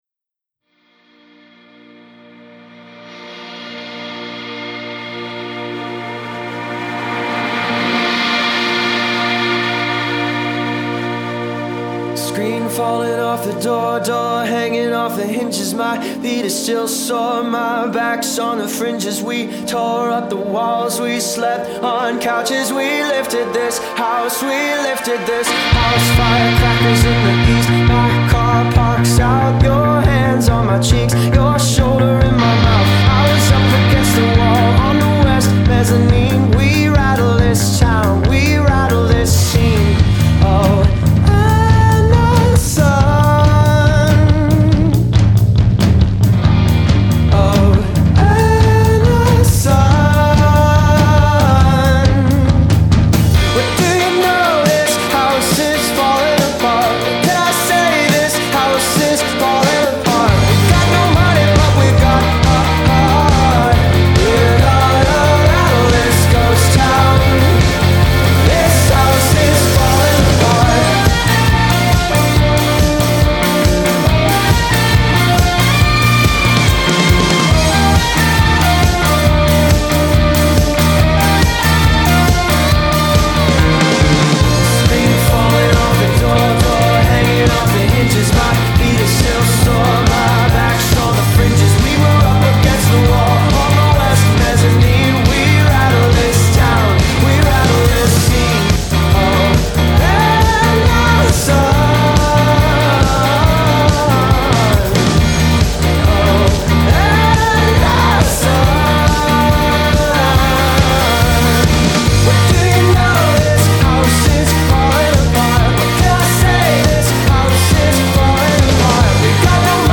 All very bright, booty-shaking, catchy, poptastical cuts.
dance-rockers